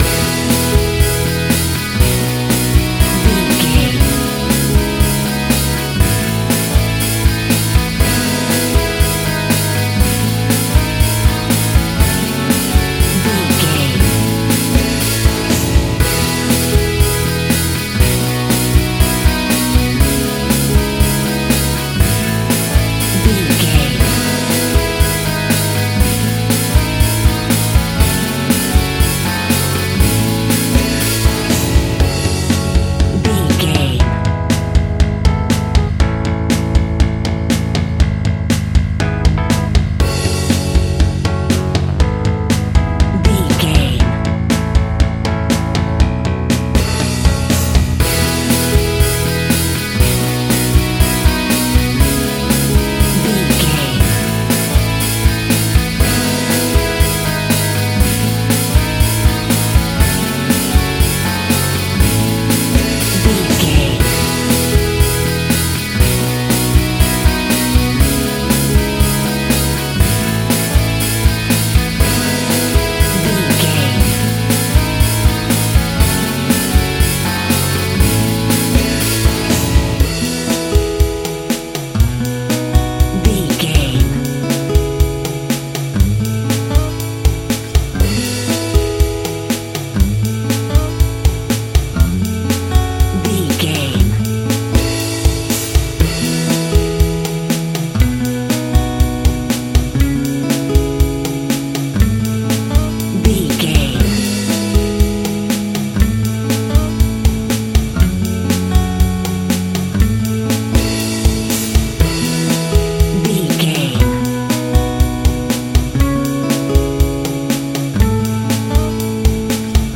Ionian/Major
happy
energetic
uplifting
electric guitar
bass guitar
drums
indie rock
acoustic guitar
synth keys